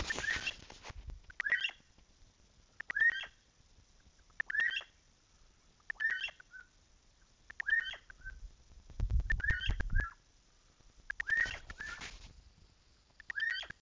Elegant Crested Tinamou (Eudromia elegans)
Cuando la Copetona emitió este sonido, desconocido para mi, salió del matorral y se mostró, para después volver a ocultarse; otras Copetonas que estaban cerca se ocultaron y callaron, por lo que supongo que debió tratarse de una especie de vocalización territorial.
Location or protected area: Reserva de Biósfera Ñacuñán
Condition: Wild
Certainty: Photographed, Recorded vocal